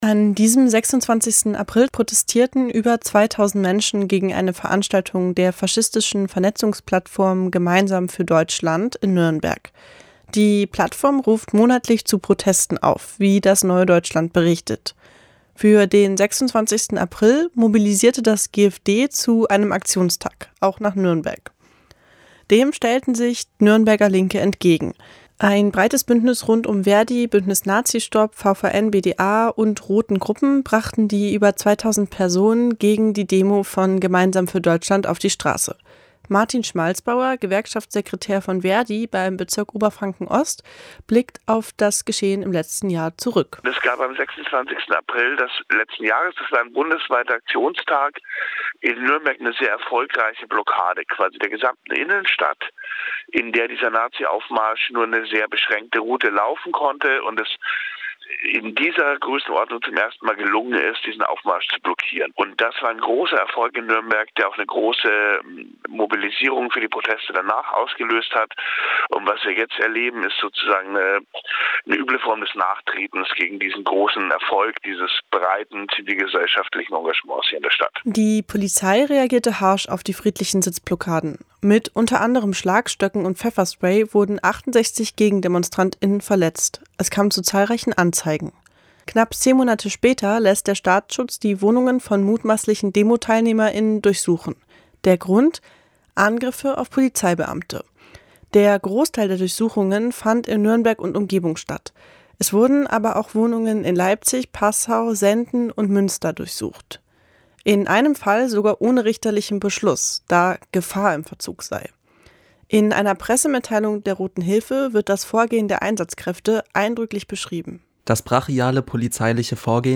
The video is the first time I ever played or sang the song with any words beyond the refrain "Sleazo Drumpf" so it has a lot of mumbles in it. I made up the words as I went along but they came out well enough that I wanted to post it.